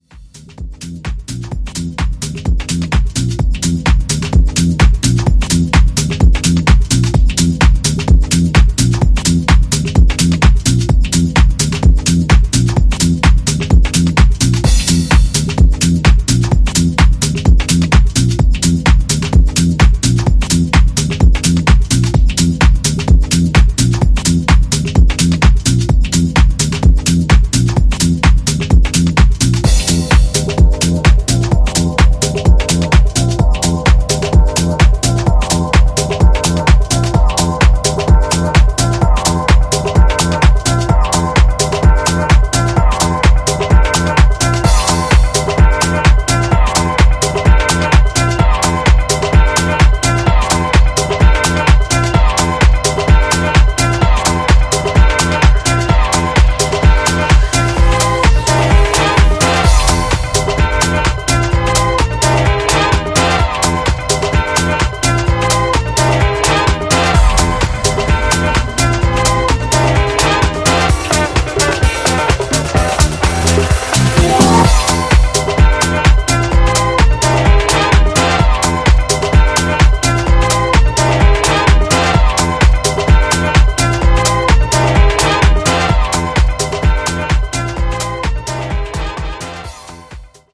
American producer
contemporary underground house
from Disco to Jackin to Soulful To Deep Sounds.